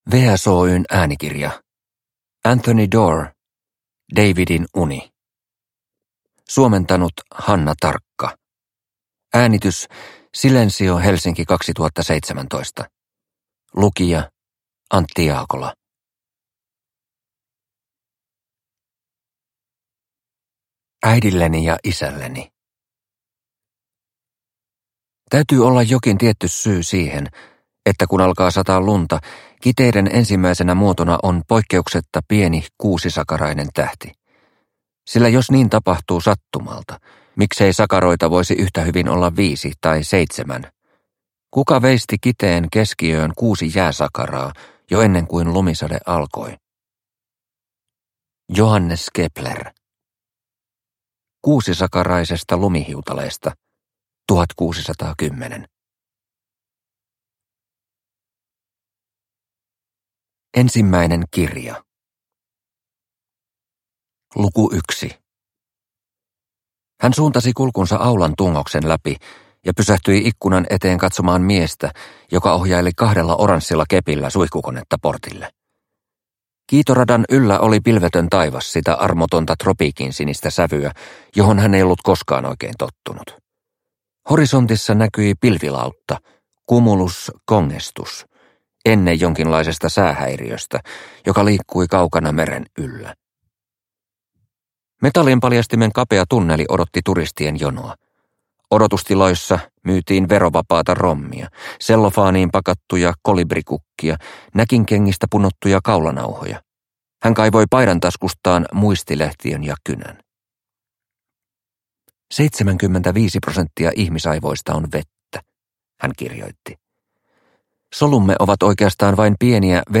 Davidin uni – Ljudbok – Laddas ner